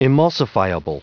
Prononciation du mot emulsifiable en anglais (fichier audio)
emulsifiable.wav